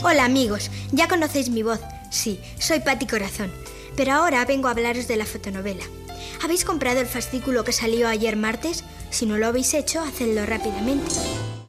Anunci de la fotonovel·la del serial radiofònic "Patty corazón"